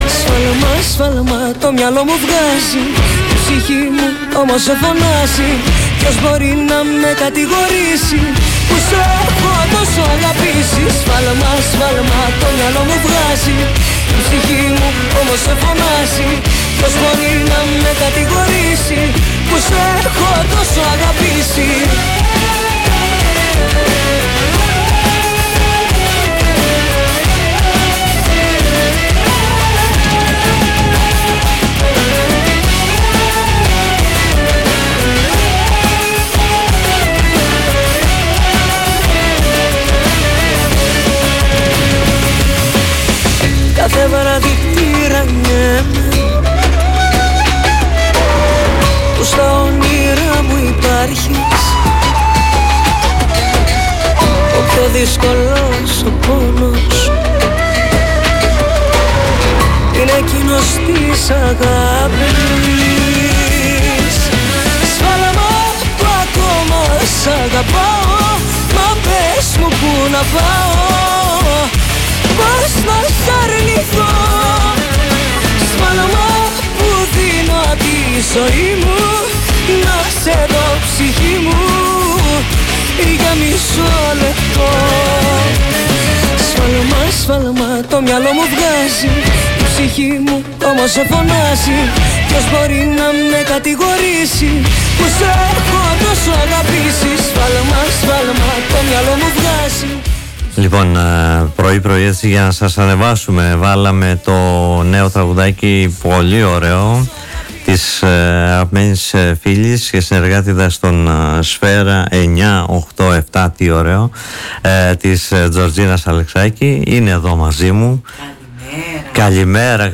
στο στούντιο του politica 89.8